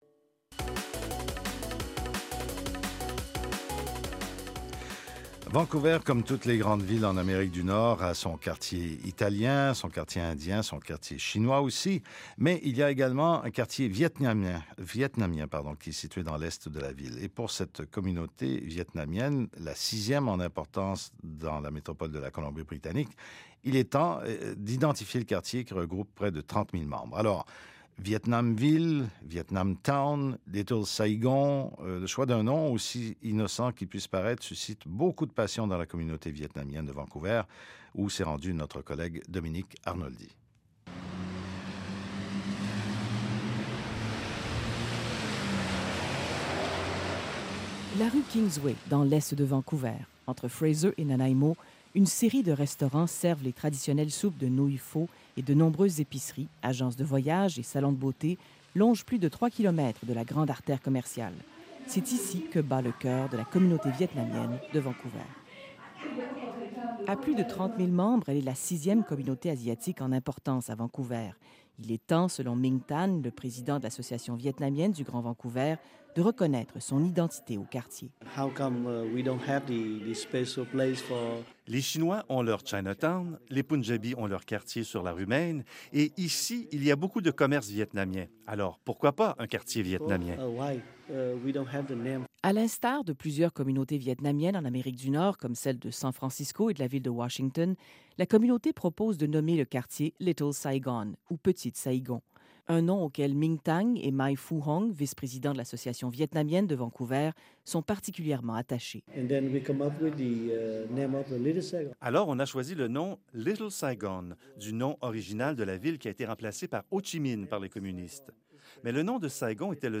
Vietnam ville, Vietnam Town ou Little Saigon? Le choix d’un nom, aussi innocent qu’il puisse paraître, suscite beaucoup de passions dans la communauté vietnamienne de Vancouver. Le reportage